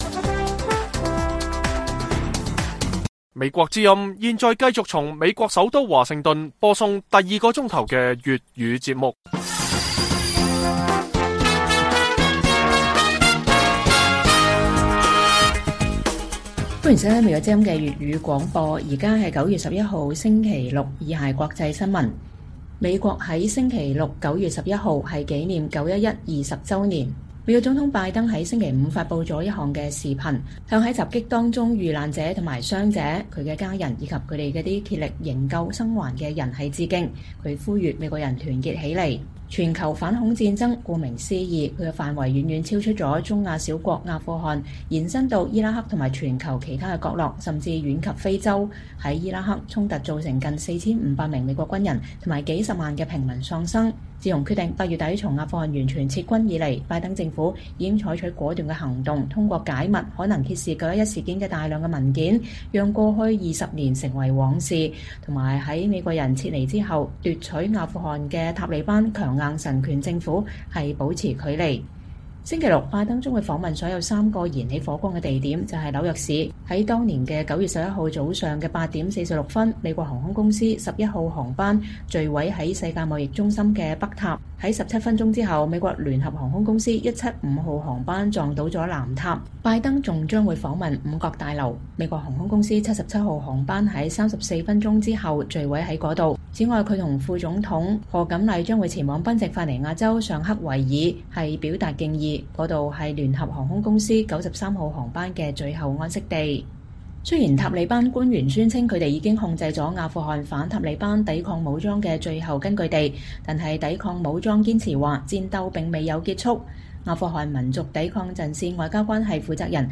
粵語新聞 晚上10-11點：9/11事件20週年後 拜登尋求一個時代的終結